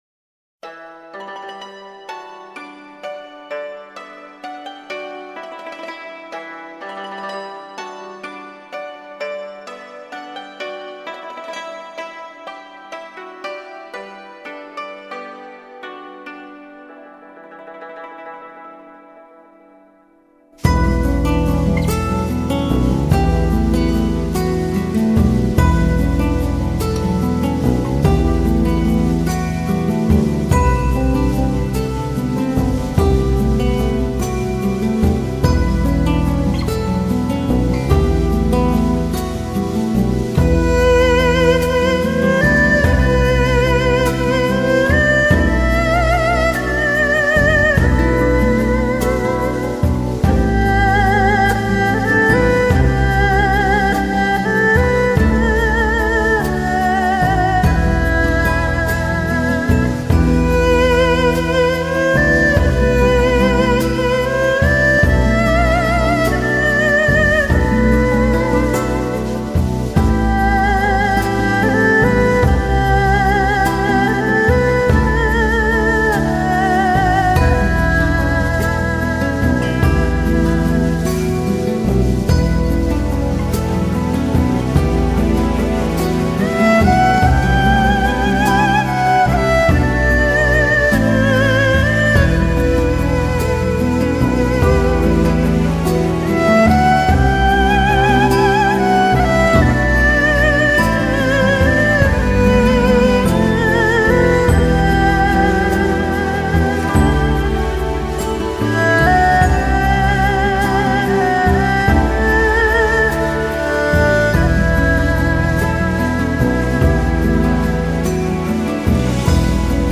ETHNO, LOUNGE, CHILLOUT, AMBIENT, DOWNTEMPO, NEW AGE